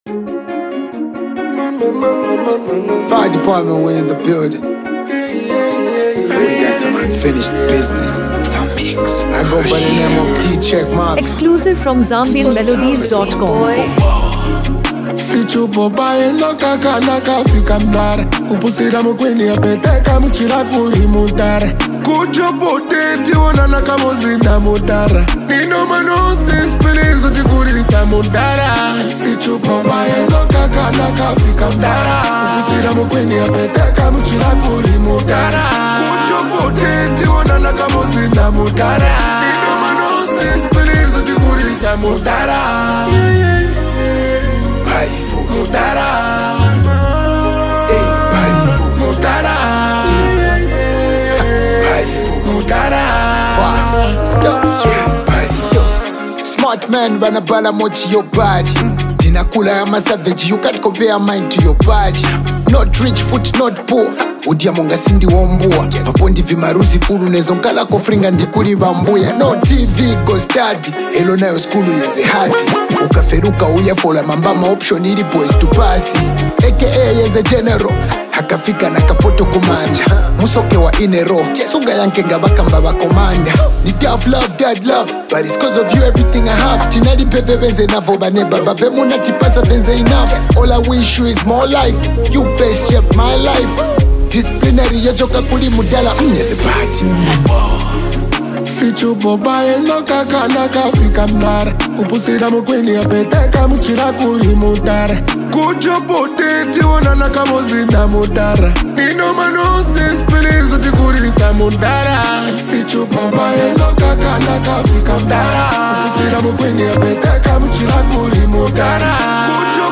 Zambian hip-hop track
Genre: Afro-beats